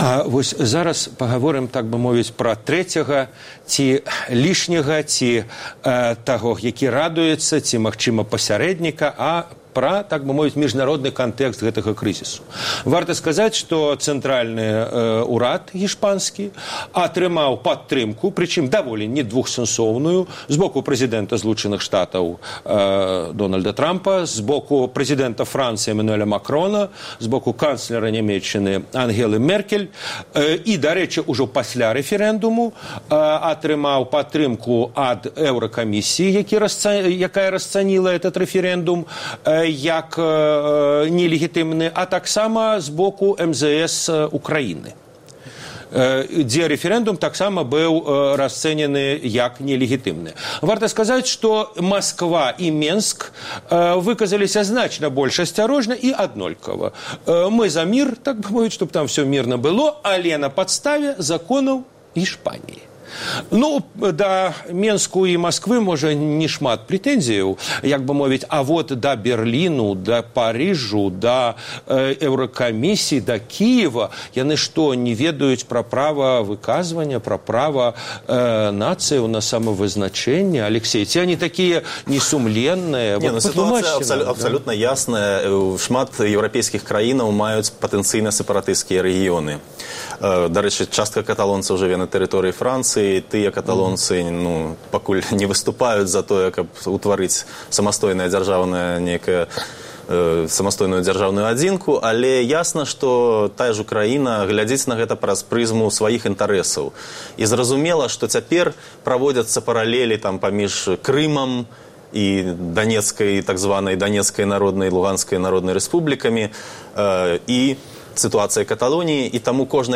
Зона Свабоды - штотыднёвая аналітычная перадача на тэлеканале Белсат Тэма выпуску – рэфэрэндум аб незалежнасьці Каталёніі. Што важней: права на самавызначэньне ці тэрытарыяльная цэласнасьць?